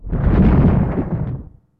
Super Smash Bros. game sound effects
Rumble.wav